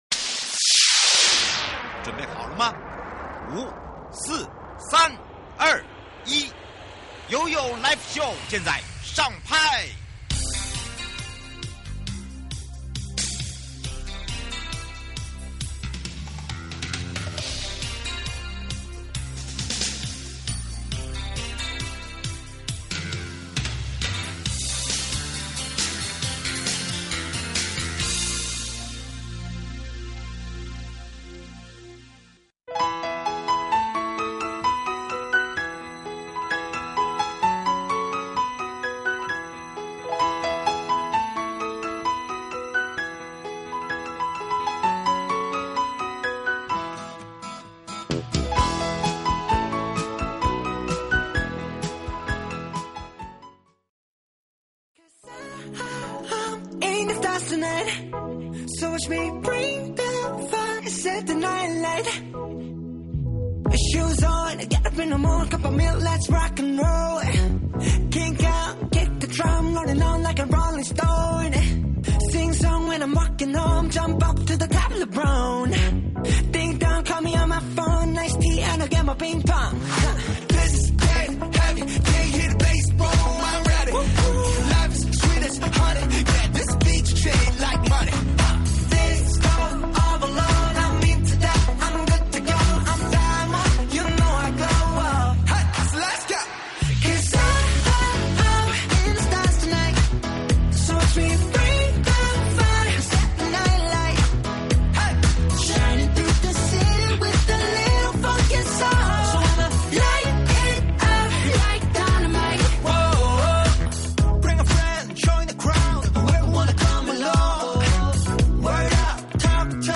受訪者： 花東縱谷管理處 許宗民處長